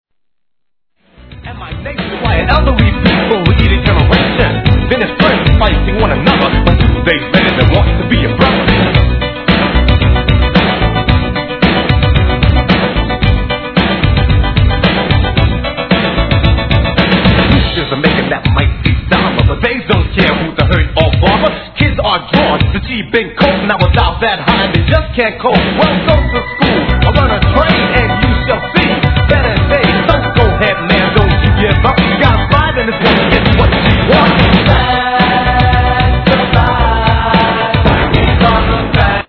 HIP HOP/R&B
似の打ち込みにエレクトロな上音がはまった'85年OLD SCHOOL!!!